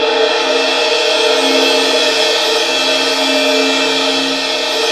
Index of /90_sSampleCDs/Roland LCDP03 Orchestral Perc/CYM_Cymbal FX/CYM_Stick Rolls
CYM CYMB 00L.wav